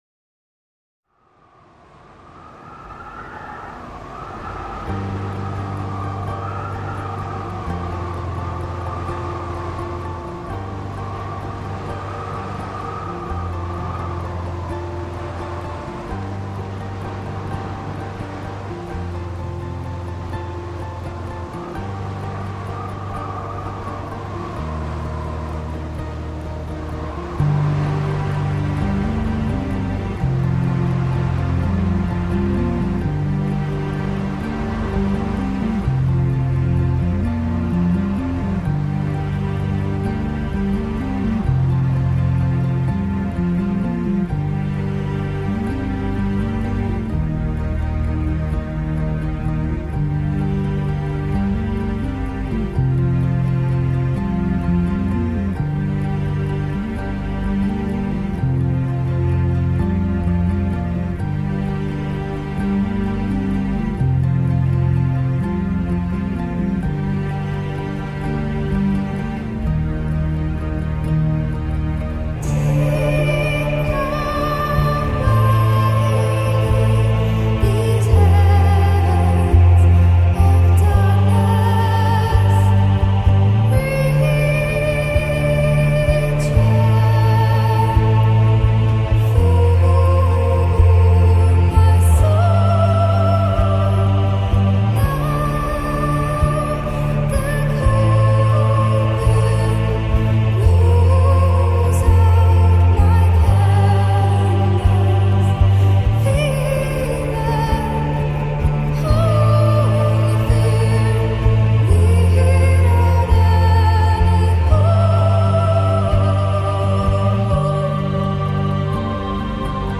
BPM128-256
Audio QualityPerfect (High Quality)